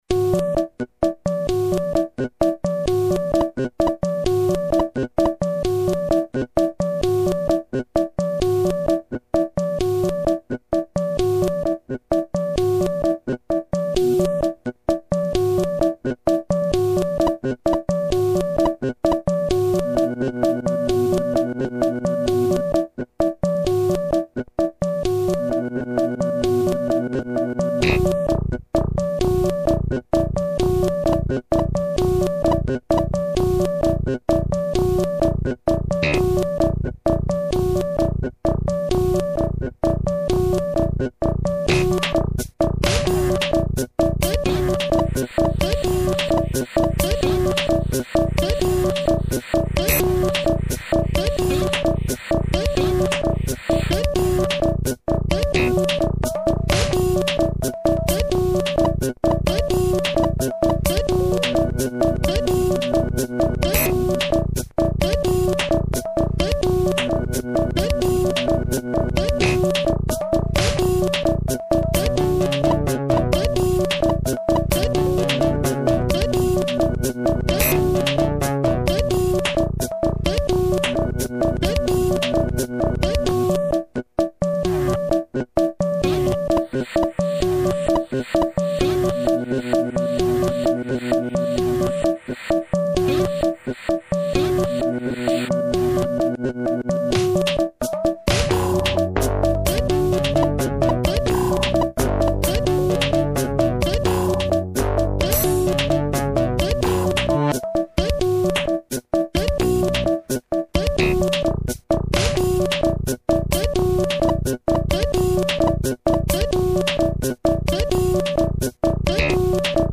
pop,jazz,experimental